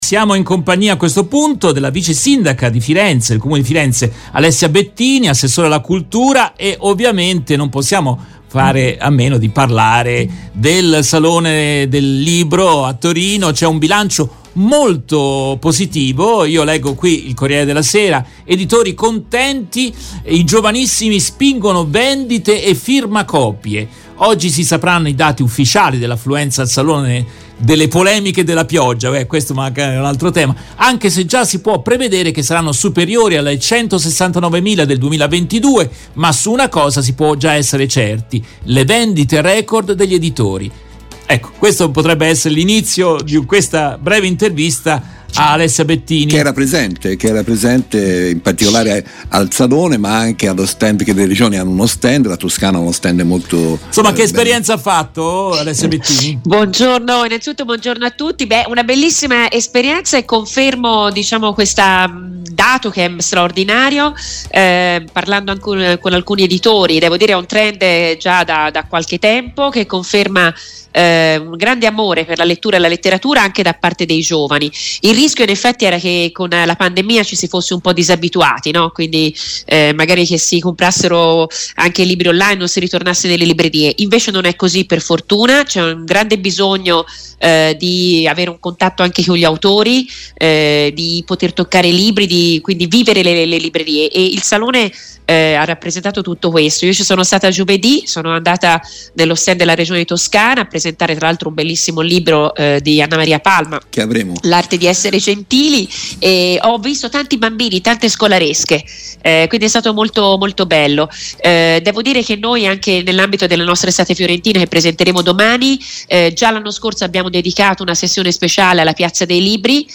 Interviste dal Salone del libro di Torino - HopeMedia Italia